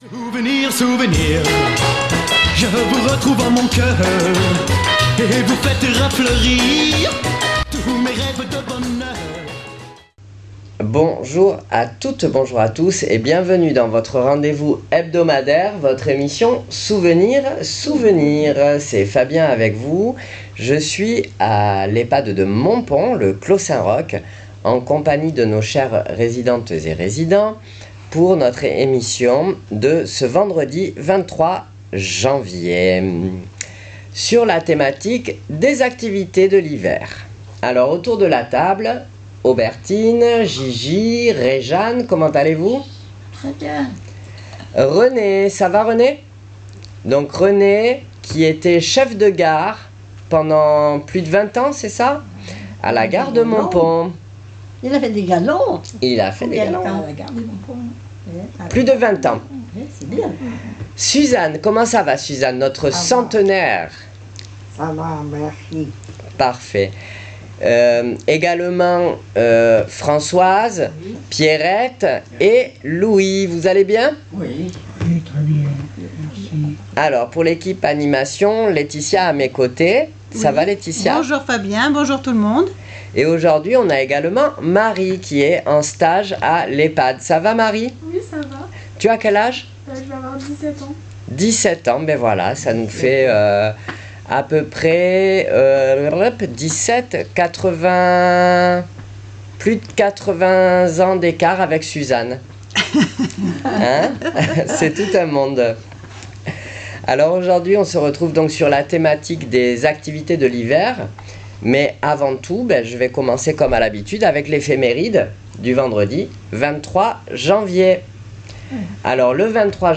Souvenirs Souvenirs 23.01.26 à l'Ehpad de Montpon " Les occupations d'hiver "